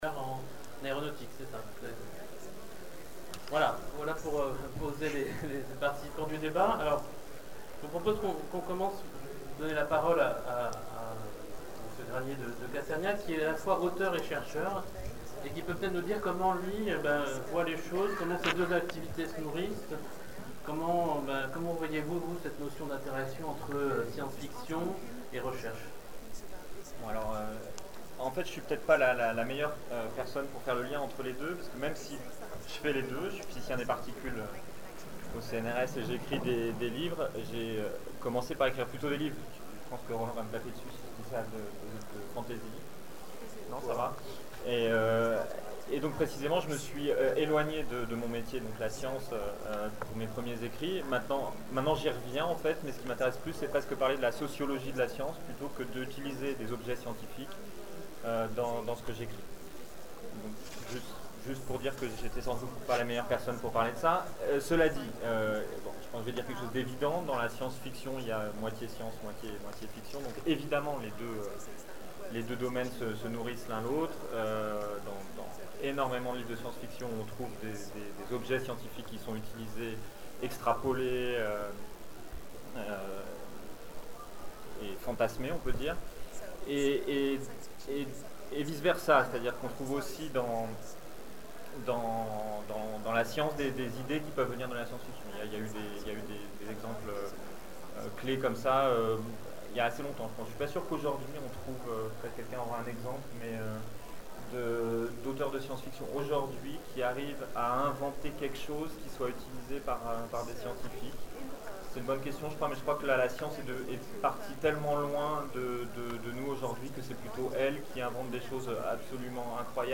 (Attention, le son n'est pas très bon)